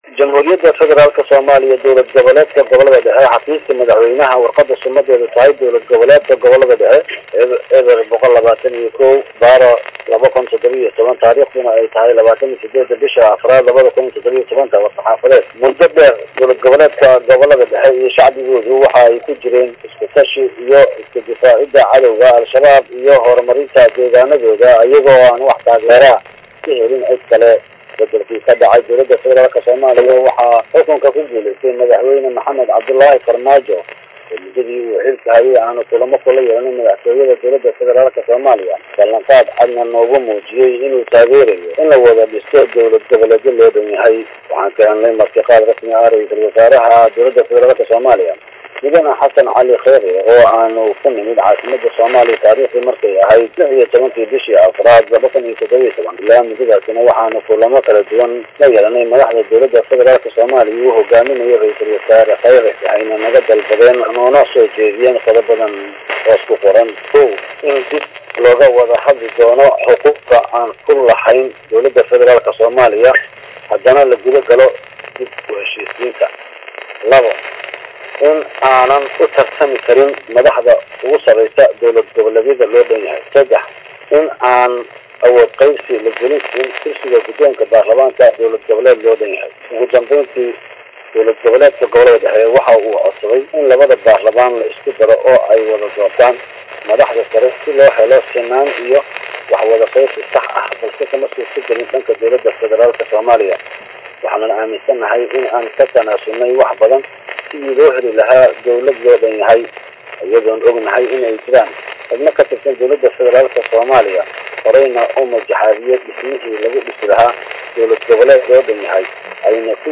ayaa warbaahinta u aqriyay war-murtiyeedka ka soo baxay Ahlusuna.